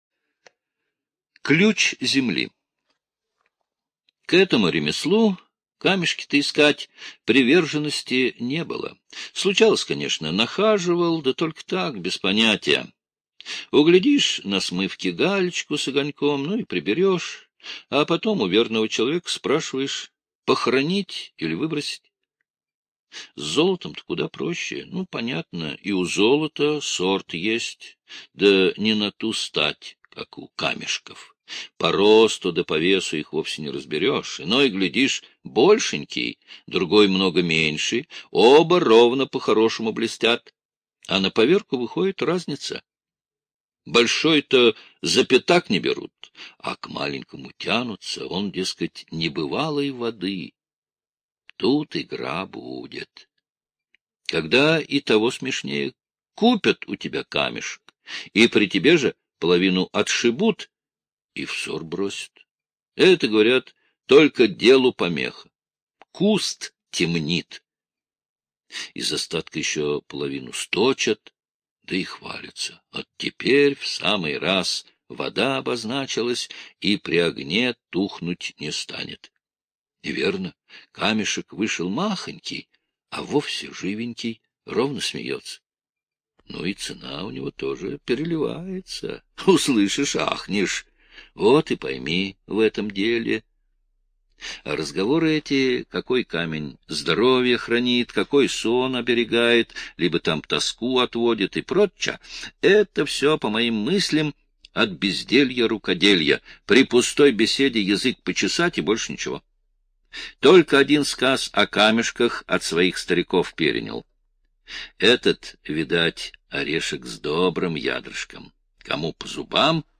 Ключ земли - аудиосказка Павла Бажова - слушать онлайн